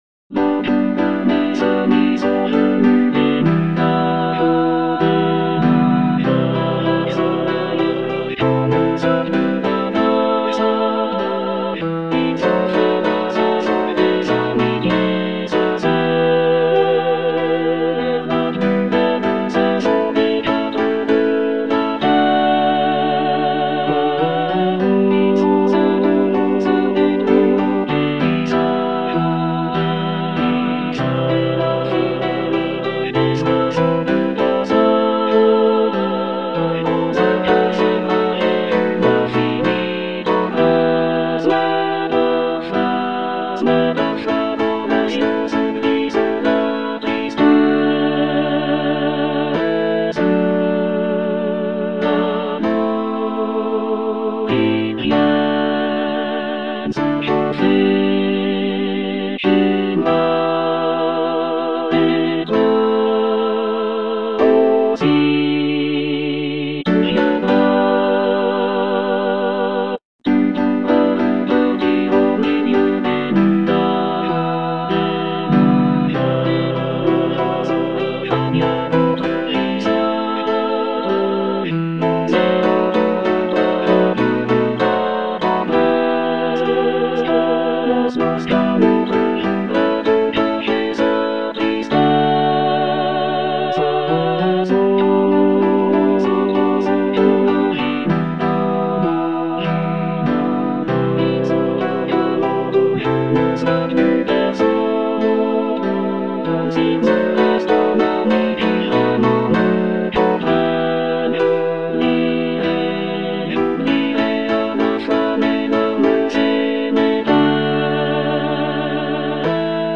All voices
piece for choir